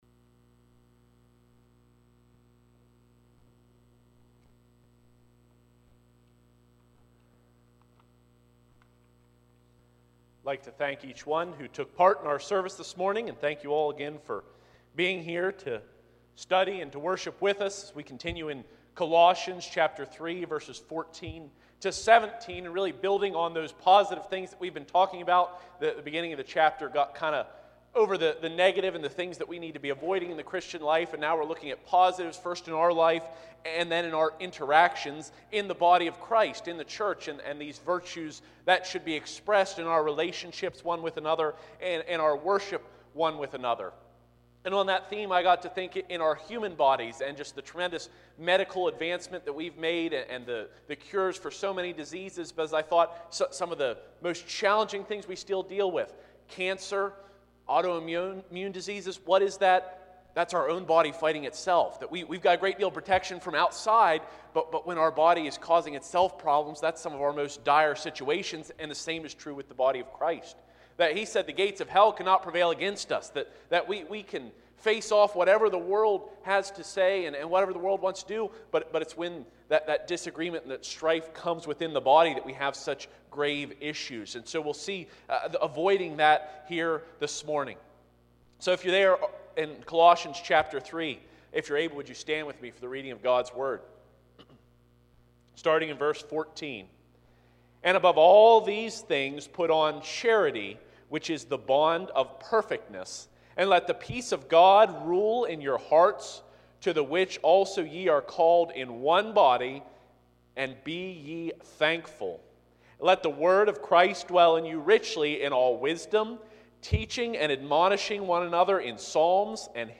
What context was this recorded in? Colossians 3:14-17 Service Type: Sunday 9:30AM I. Love and Peace v. 14-15 II.